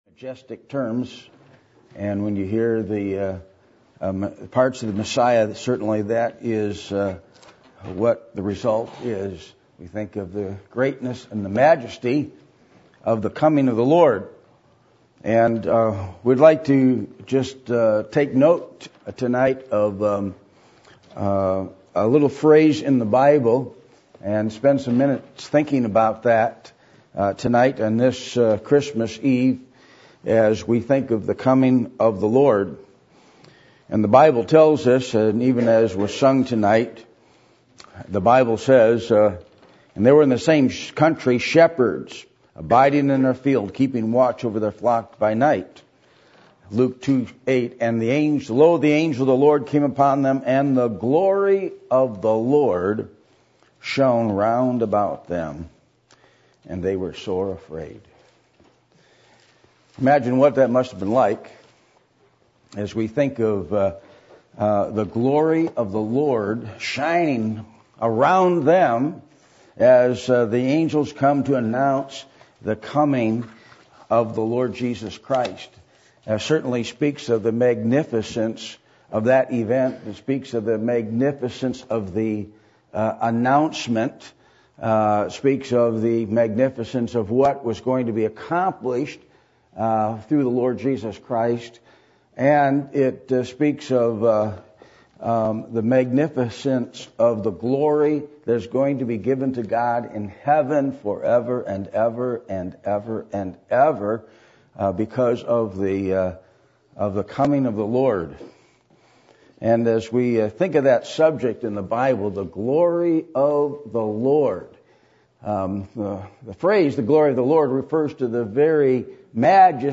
1 Corinthians 6:19 Service Type: Sunday Evening %todo_render% « Do You Know Jesus